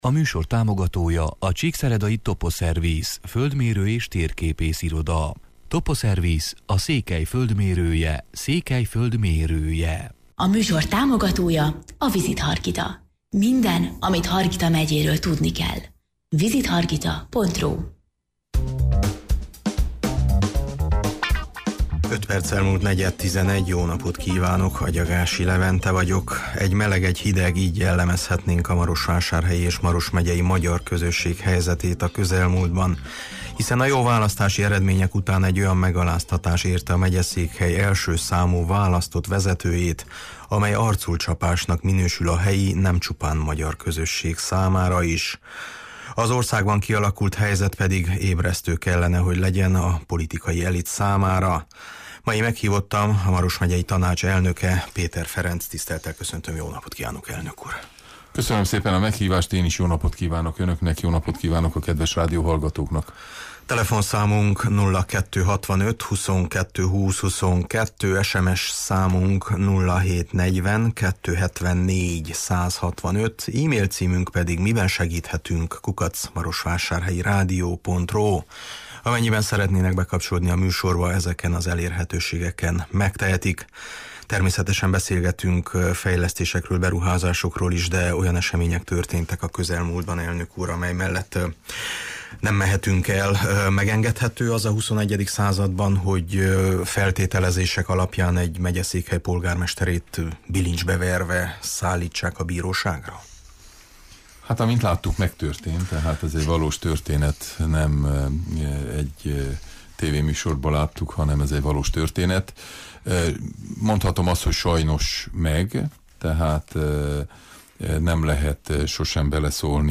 Meghívottam a Maros Megyei Tanács elnöke, Péter Ferenc: